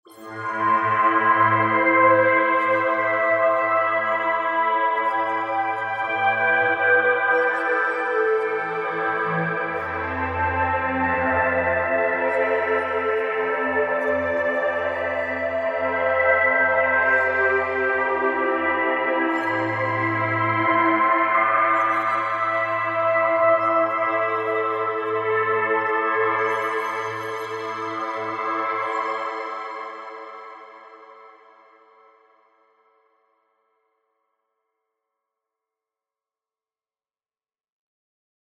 第7回：ウェーブテーブル波形を使用したシンセパッド音色を作成する
それを回避するためにデチューン幅を周期的に変化させることである種のアナログ的な揺らぎ効果を狙っています。
また、LFO 5については、シンプルにWARPエフェクトの効果を周期的な音色変化を得るために使用しています。
なお、サウンドメイクの仕上げとしてHYBRID FILTER（図10）とSWARM REVERB（図11）を使用しています。
synthpad_demo.mp3